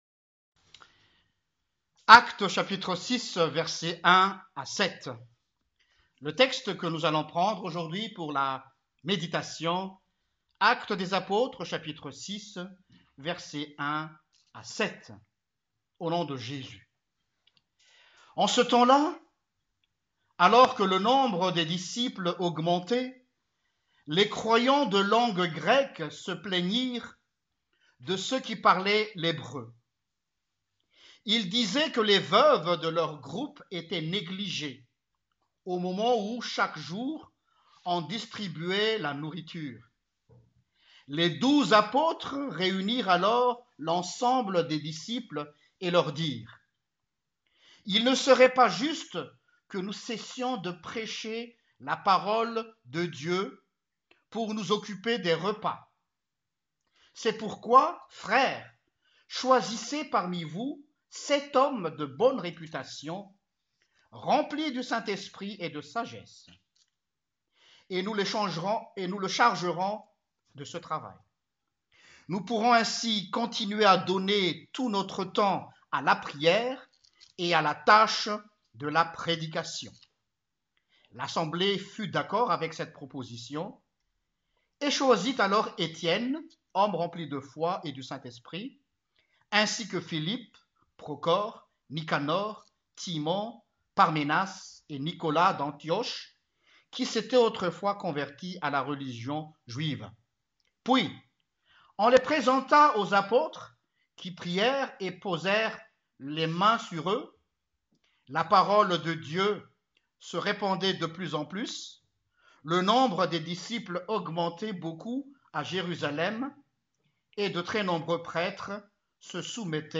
Predication-10-Mai-online-audio-converter.com_.mp3